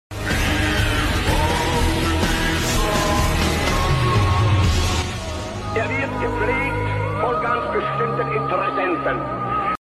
Genuine engine driven (528939km) =Called